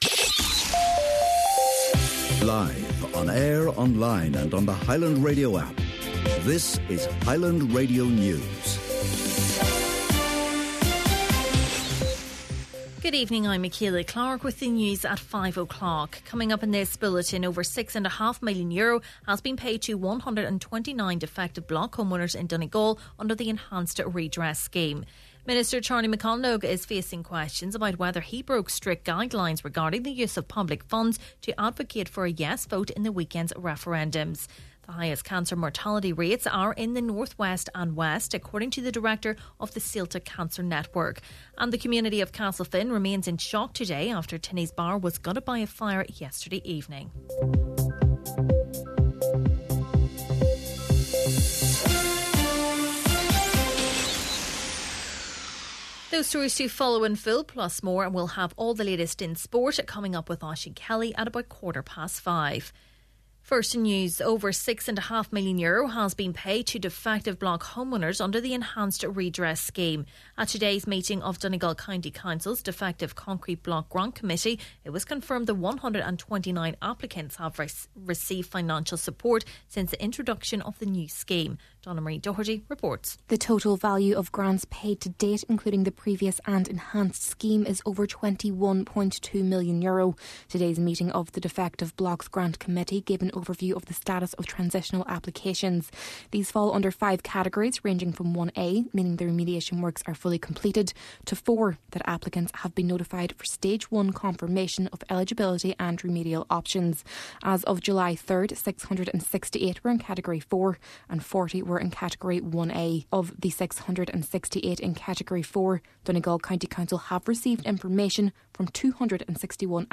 Main Evening News, Sport and Obituaries – Monday March 11th